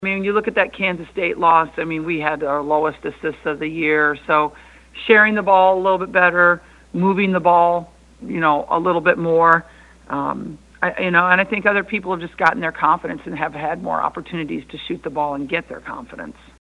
That’s Iowa coach Lisa Bluder.